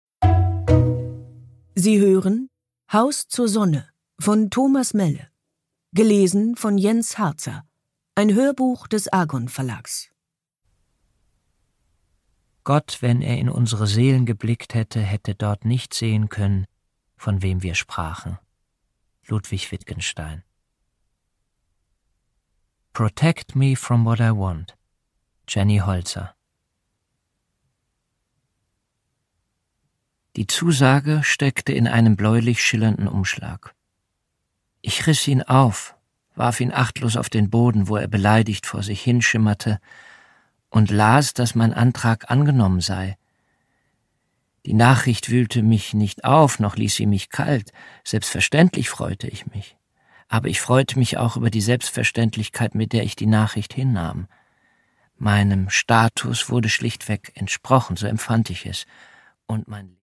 Produkttyp: Hörbuch-Download
Gelesen von: Jens Harzer